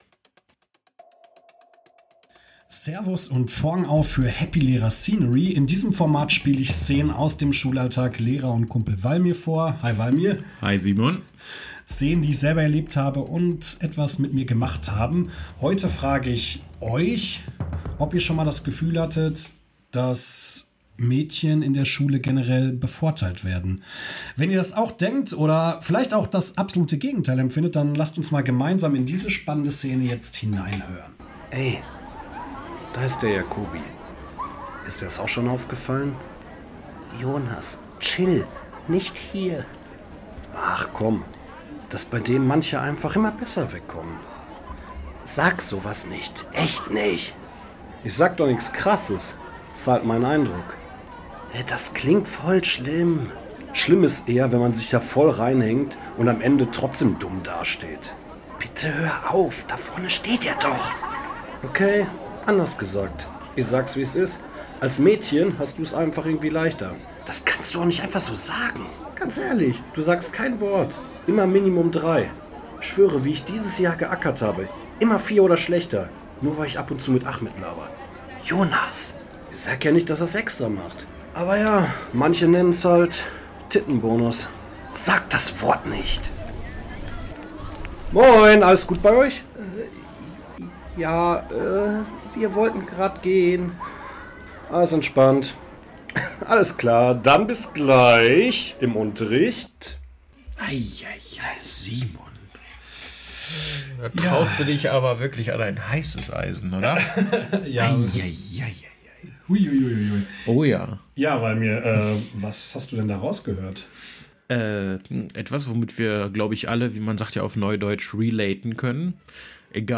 Beschreibung vor 2 Monaten Zwei Schüler auf dem Pausenhof tuscheln und sprechen es aus!